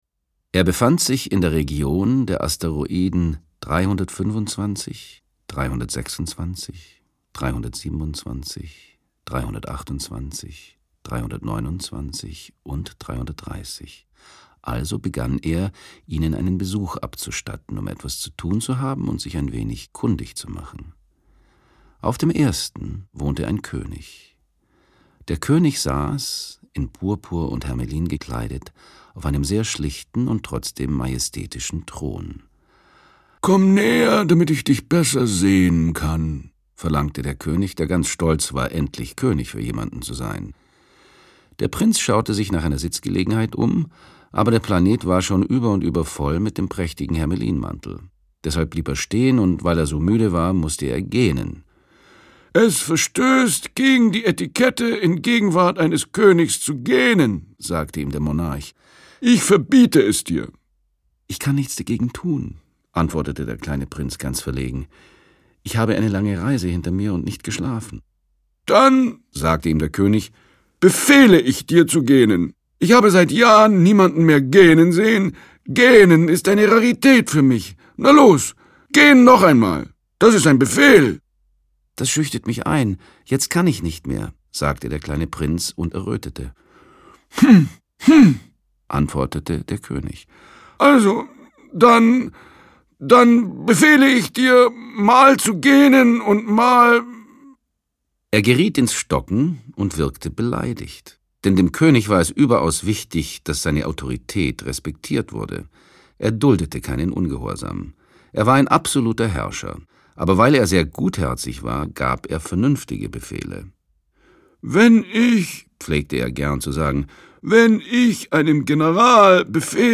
AUgust Zirner (Sprecher) Audio-CD 2016